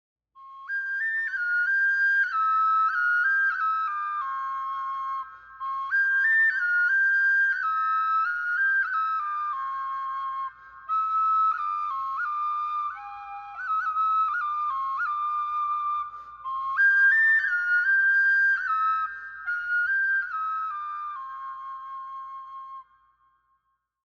Traditional tune on salamuri (shepherd’s sound effects free download
Traditional tune on salamuri (shepherd’s flute from Georgia)